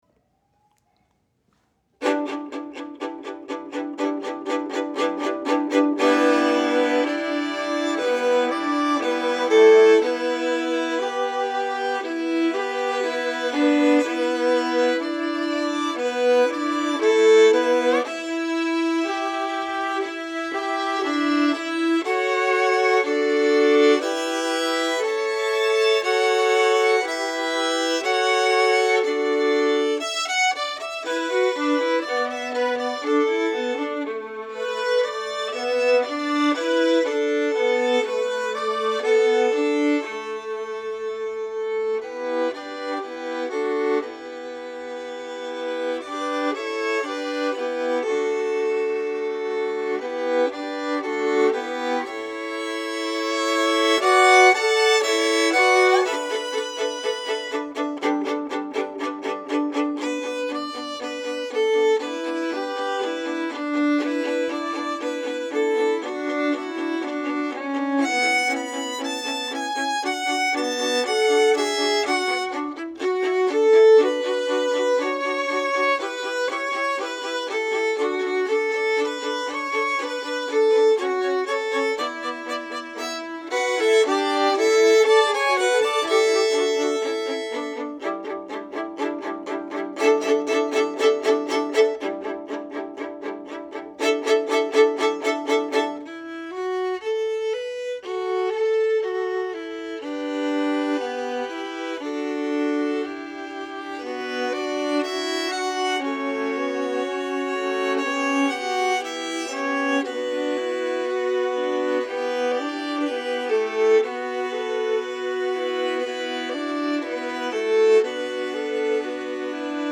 Voicing: String Ens